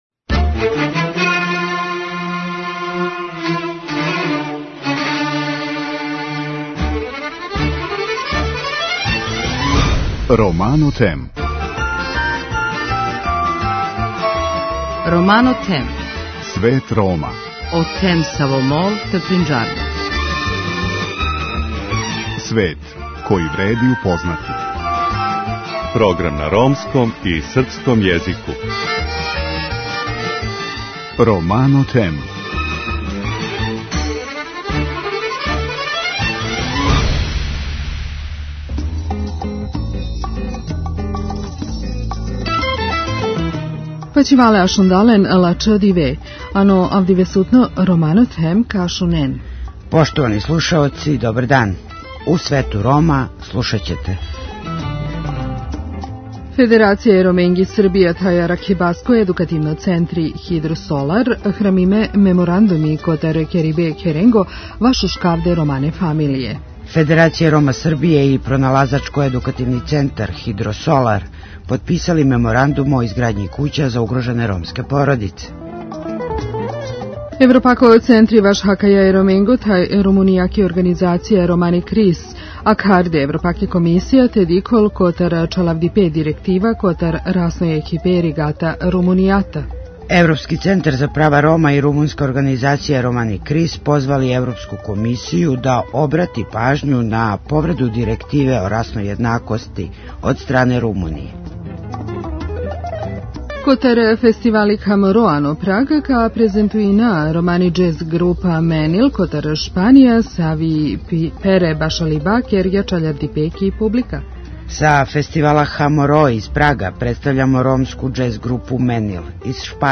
Са Фестивала Кхаморо из Прага, представљамо ромску џез групу Менил из Шпаније која је својим наступом одушевила публику.